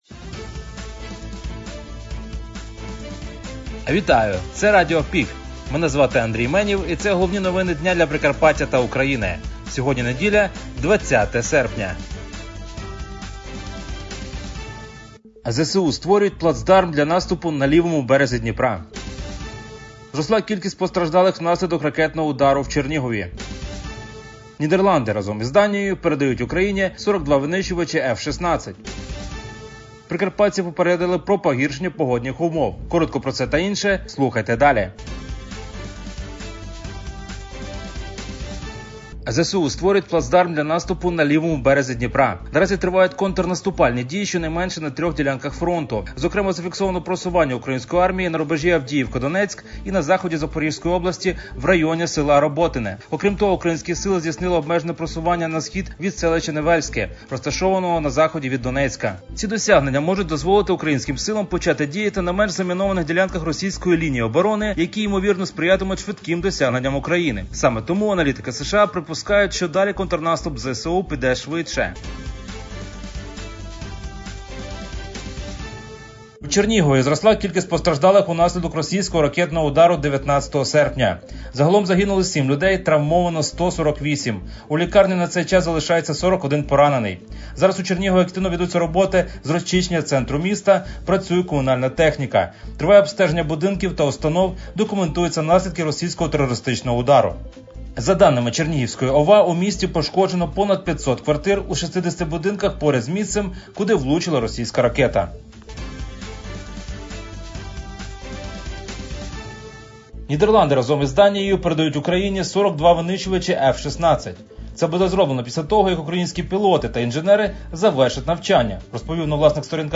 Пропонуємо вам актуальне за день - у радіоформаті.